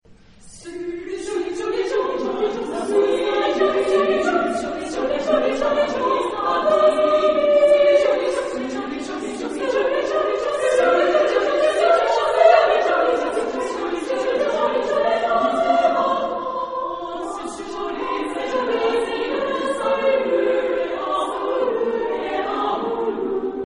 Género/Estilo/Forma: Canción ; Renacimiento ; Profano
Tipo de formación coral: SAB O TTB  (3 voces Coro de hombres O Coro mixto )
Tonalidad : fa mayor
Ref. discográfica: Internationaler Kammerchor Wettbewerb Marktoberdorf 2007